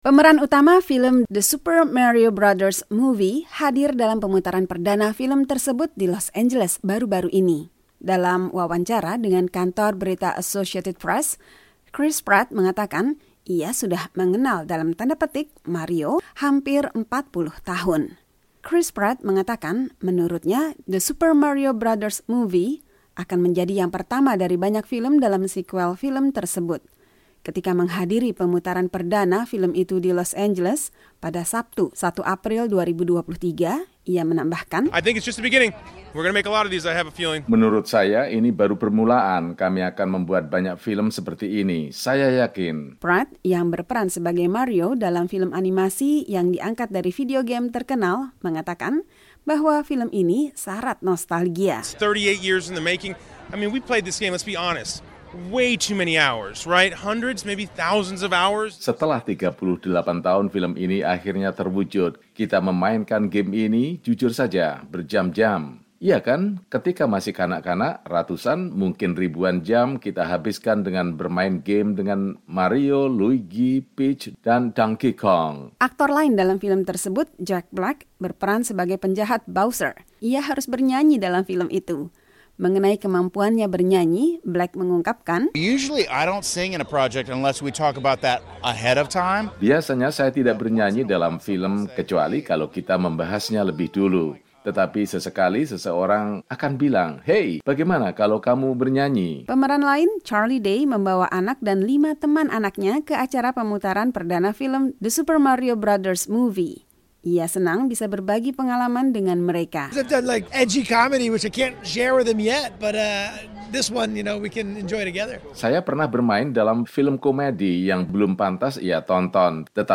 Pemeran utama film The Super Mario Bros. Movie hadir dalam pemutaran perdana film tersebut di Los Angeles baru-baru ini. Dalam wawancara dengan kantor berita Associated Press, Chris Pratt mengatakan ia sudah ‘mengenal’ Mario hampir 40 tahun.